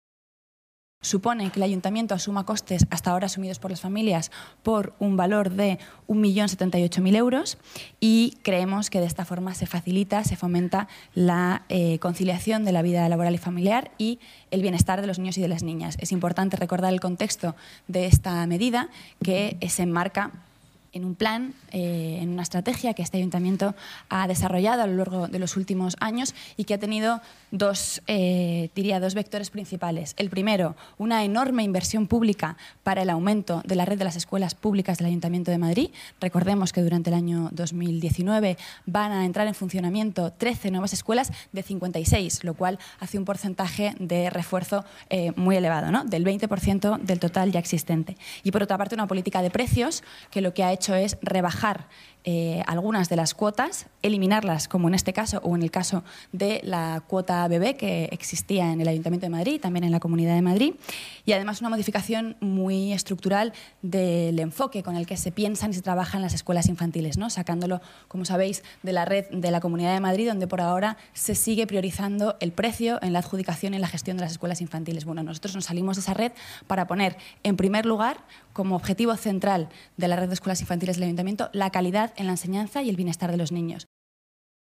La portavoz municipal, Rita Maestre, explica que el Ayuntamiento asumirá costes de más de un millón de euros y que esta medida se enmarca en el compromiso municipal de ampliar el número de escuelas y reducir los costes de la